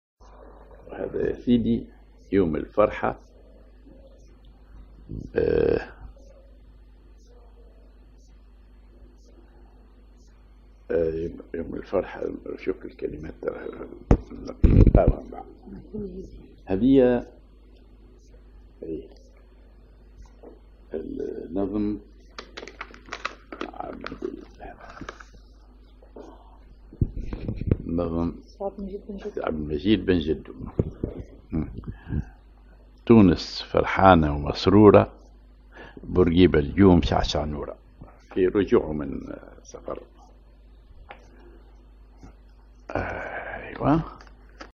Maqam ar نهاوند
genre أغنية